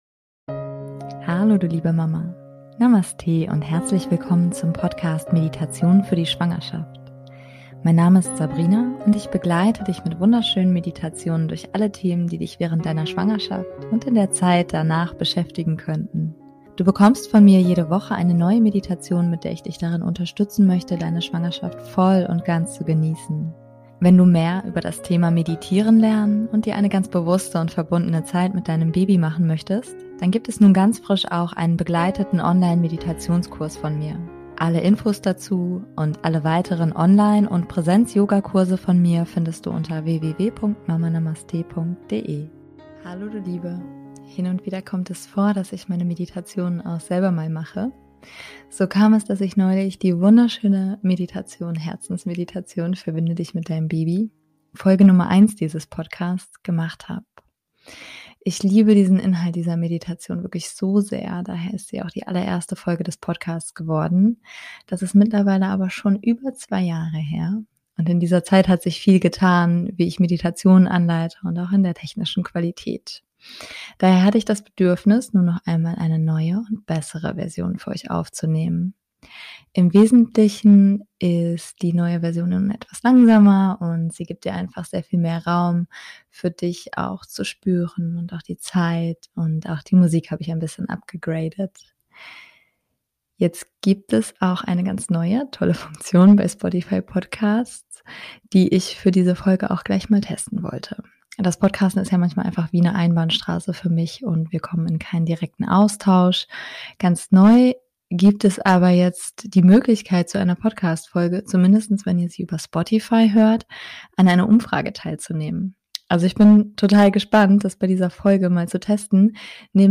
Im Wesentlichen ist diese Version nun etwas langsamer und gibt dir selbst viel mehr Raum und auch die Musik habe ich upgegradet. Meine liebste Meditation für dich, um die Schwangerschaft ganz entspannt zu genießen Mehr